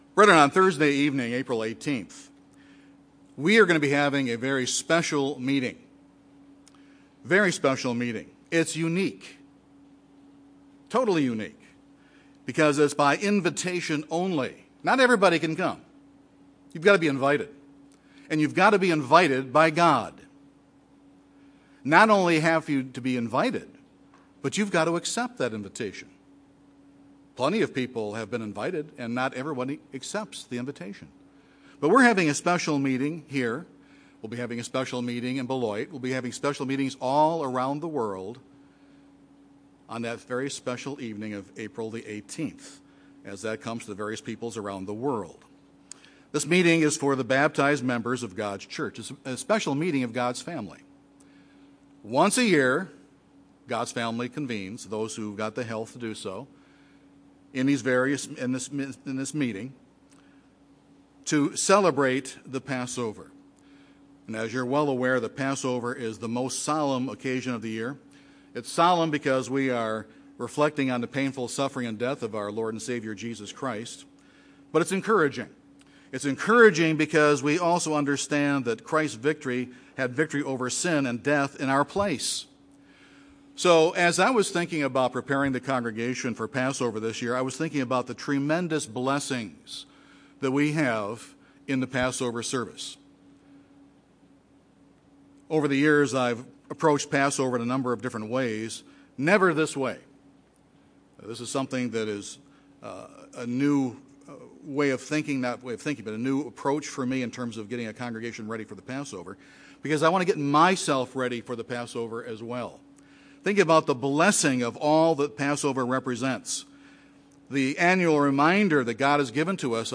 In referring to the foot washing of John 13 Jesus Christ said that we would be blessed if we washed feet. This sermon covers, in some detail, the blessings that come from humility.